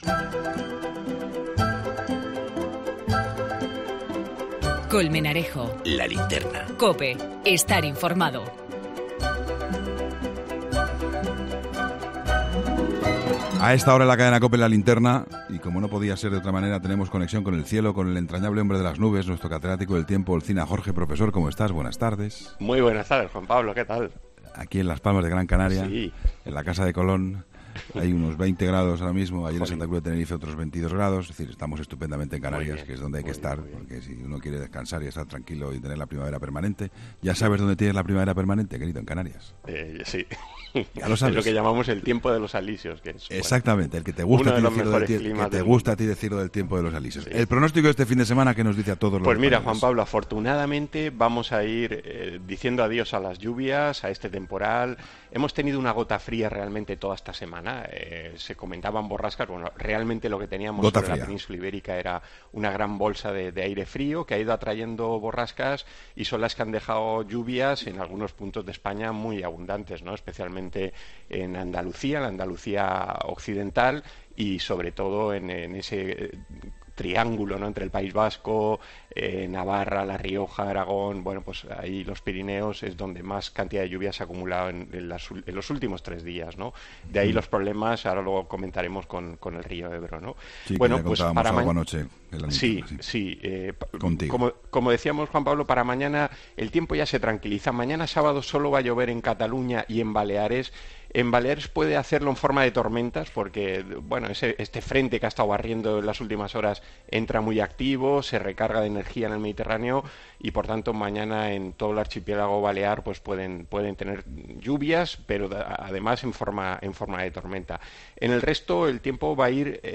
EL TIEMPO EN 'LA LINTERNA'